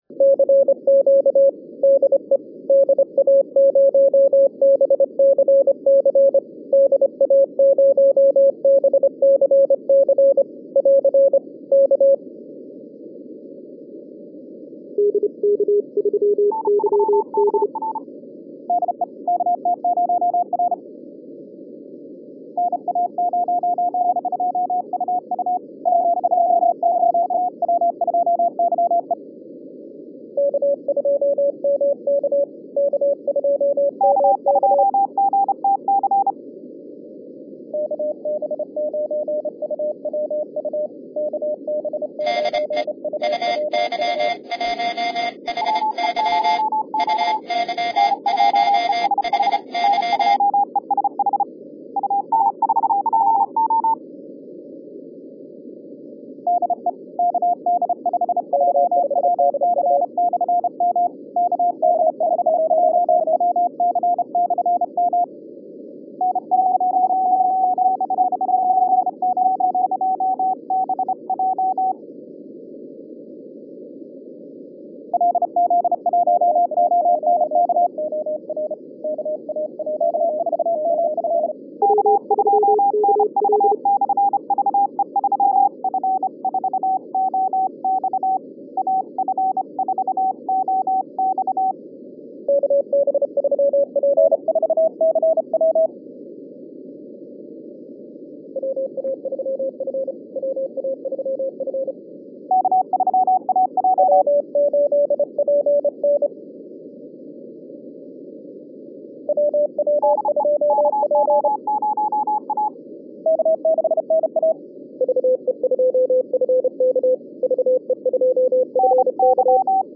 Unsere bewährten Aktivitäten am Stand – ob die BCC Challenge mit dem CW-PileUp-Wettbewerb oder die HAM Rallye für den Nachwuchs – fanden erneut Anklang.
2012 Tape BCC Challenge Friday: